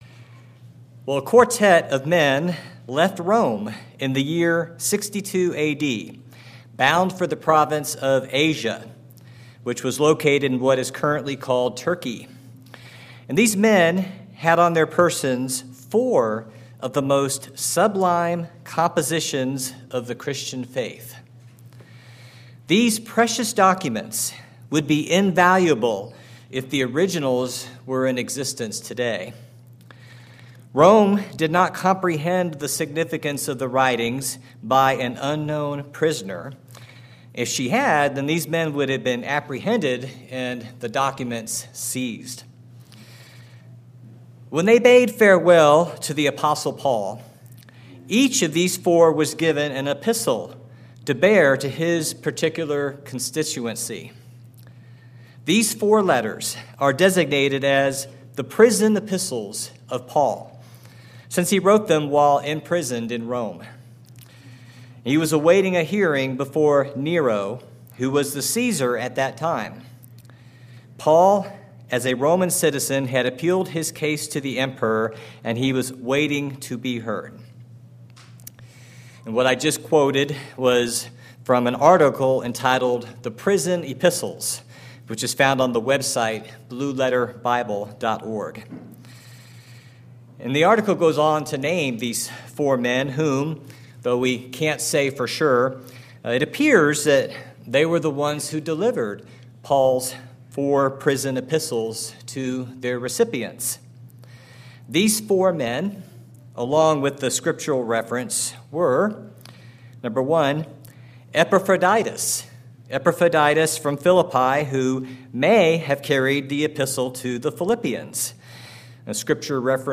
Sermons
Given in Kennewick, WA Chewelah, WA Spokane, WA